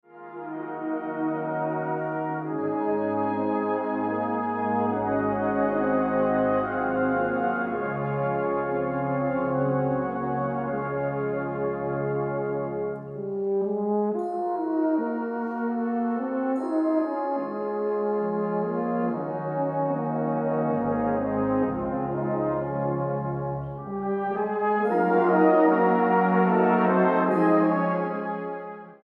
STYLE: Classical